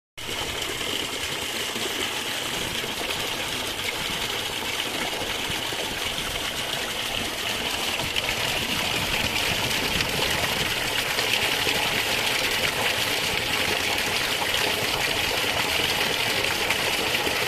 El agua rumorosa
9-jul-17.06_-fuente.mp3